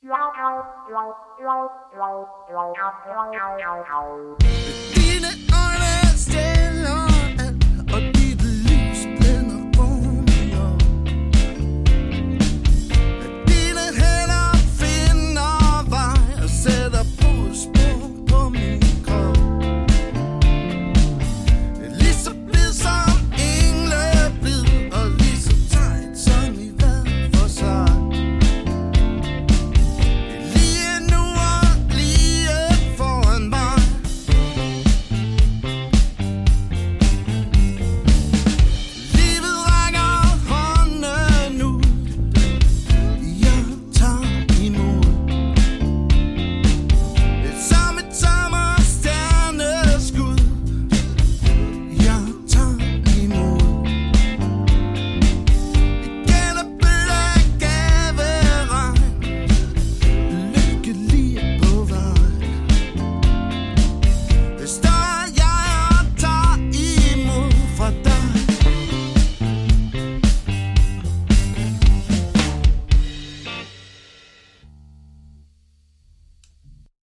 LIVE Koncert
• Coverband